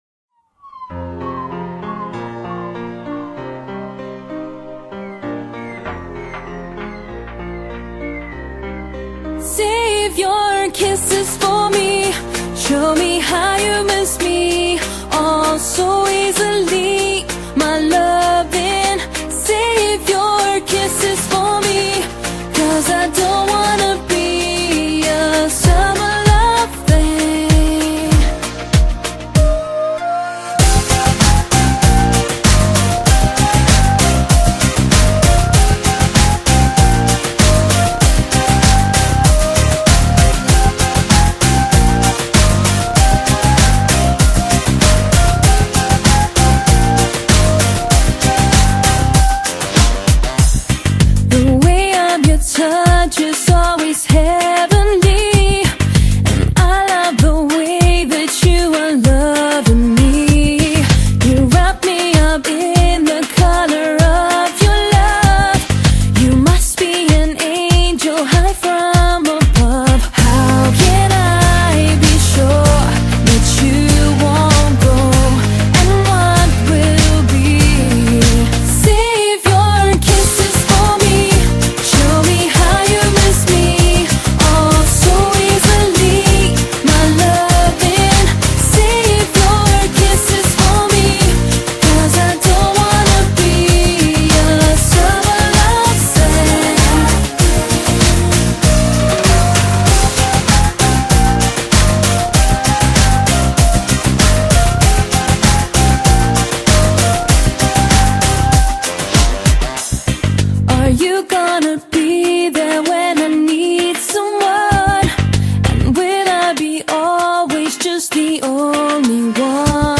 音樂類型：西洋音樂